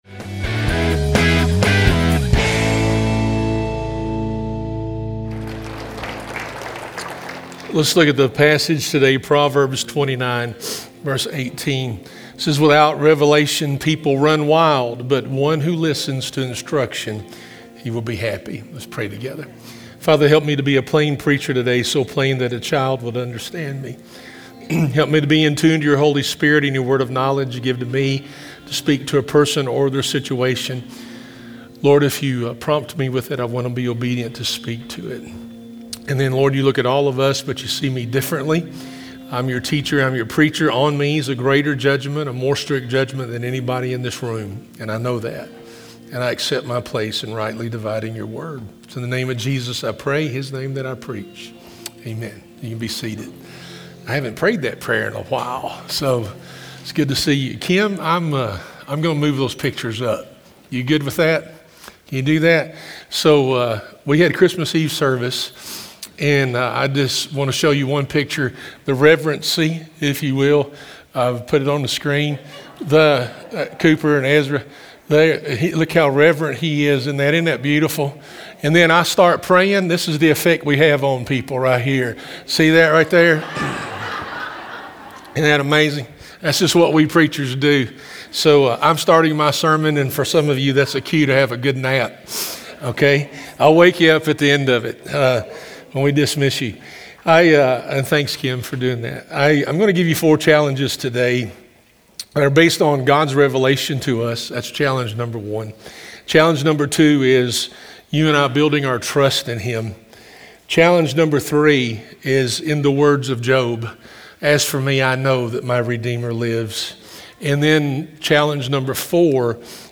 Sermons | Hope Church - Tithe